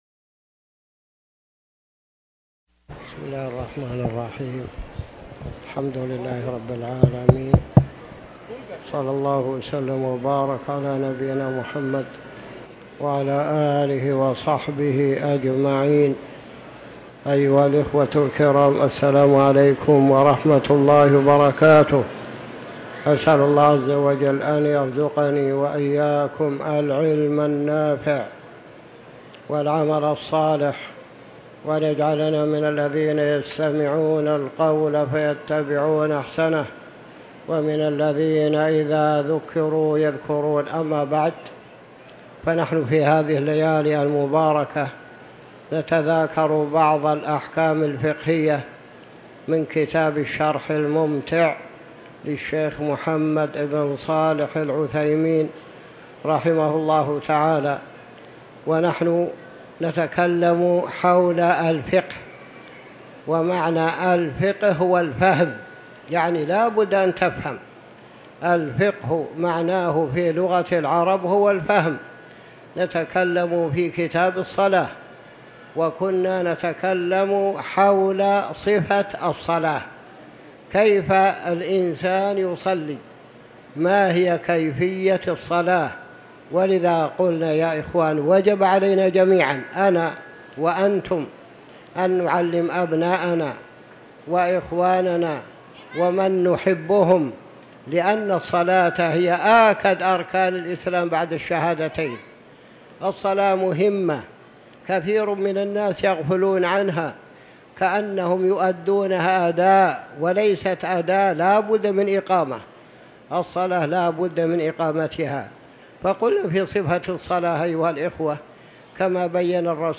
تاريخ النشر ٣٠ شوال ١٤٤٠ هـ المكان: المسجد الحرام الشيخ